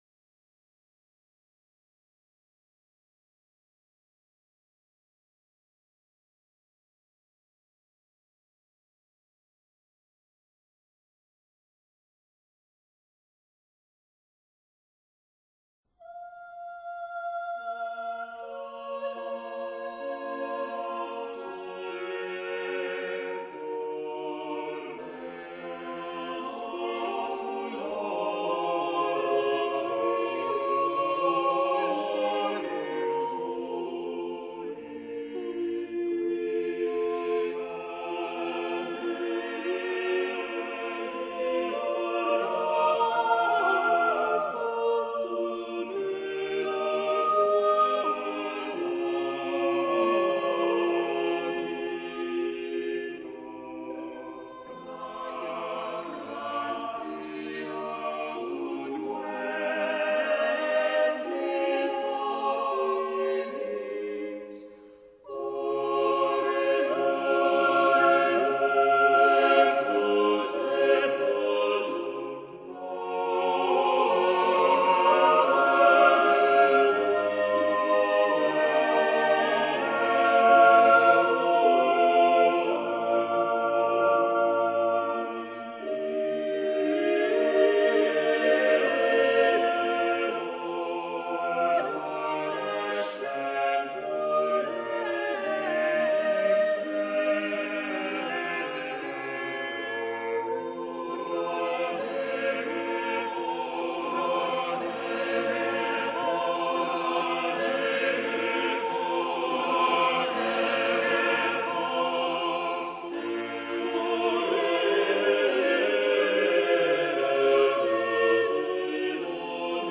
Орландо Лассо Песнь песней (восьмиголосный мотет)